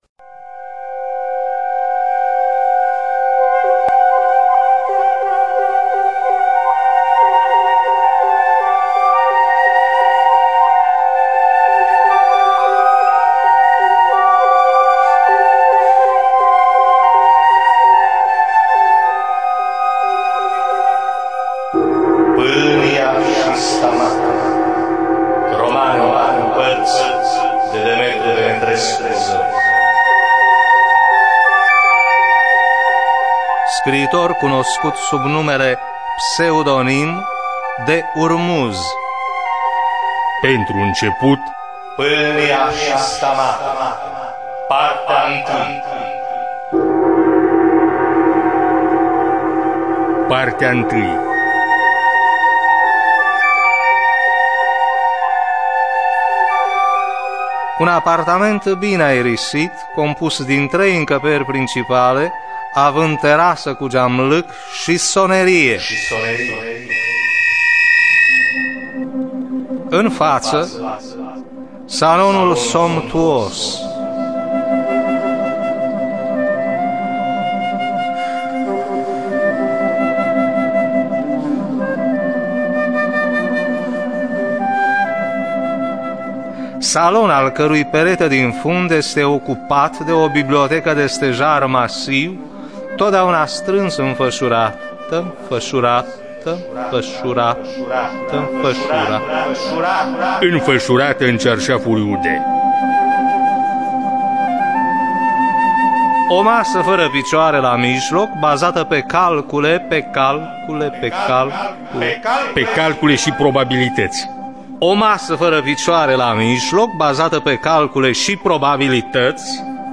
Pâlnia și Stamate de Urmuz – Teatru Radiofonic Online